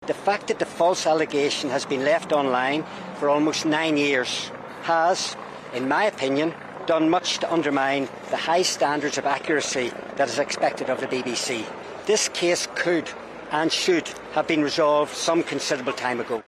has given this statement outside court: